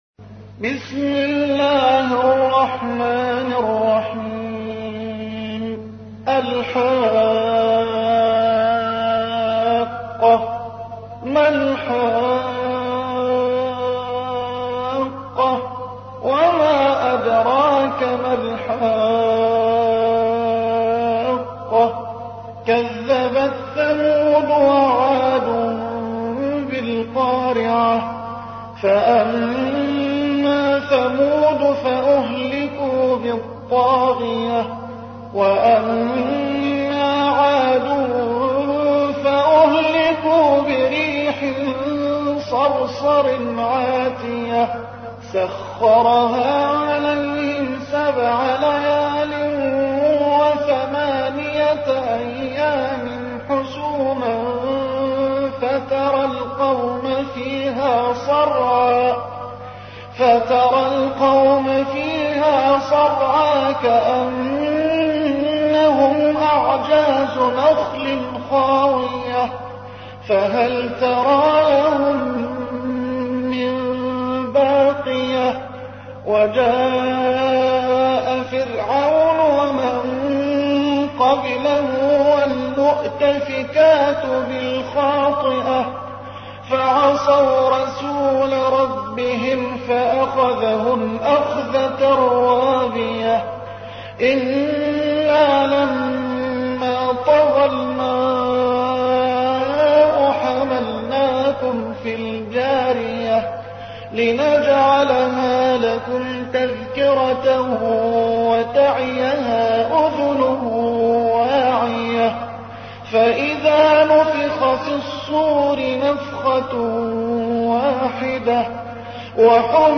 تحميل : 69. سورة الحاقة / القارئ محمد حسان / القرآن الكريم / موقع يا حسين